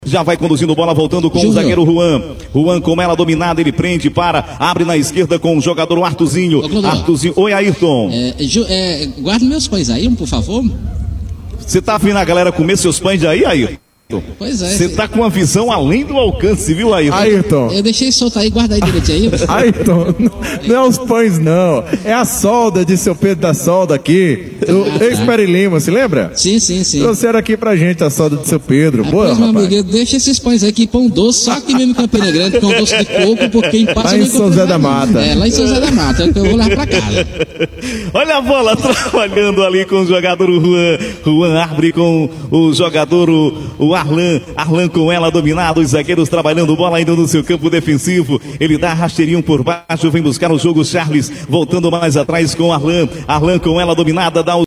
Na verdade já estavam sendo degustados pelo narrador e pelo comentarista, que se revezavam e quando um mastigava o outro narrava, e quando o narrador começava a mastigar o comentarista cobria ele comentando as jogadas.